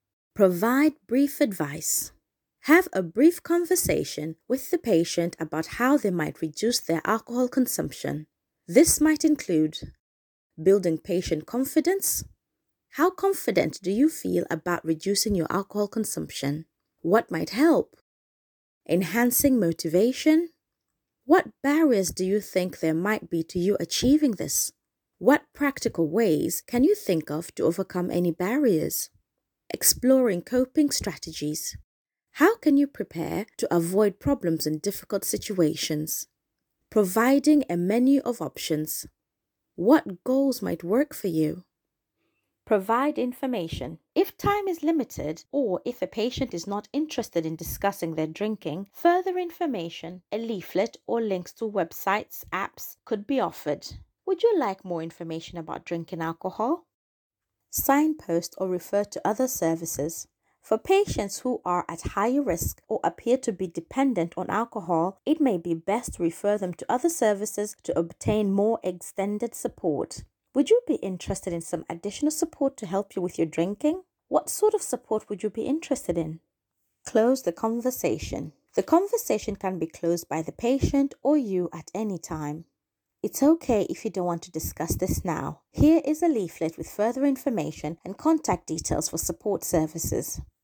Questions to help conversation - Narration